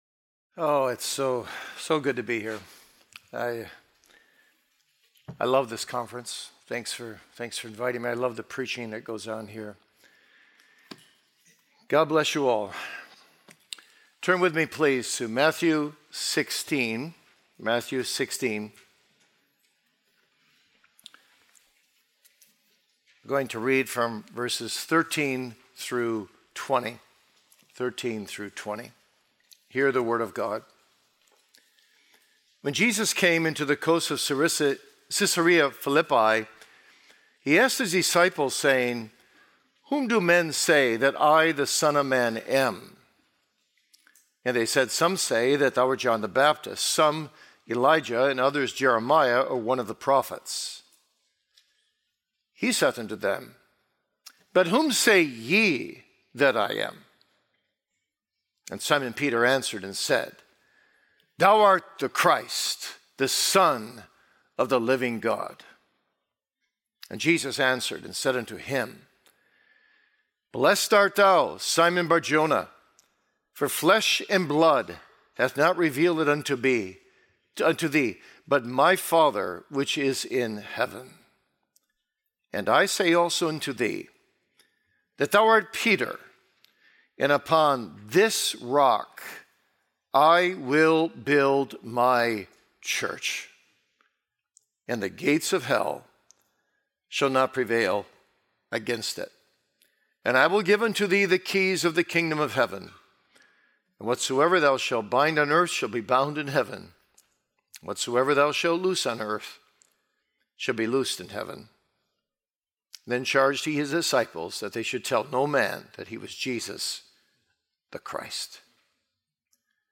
A Conference on the Local Church and Her Lifeblood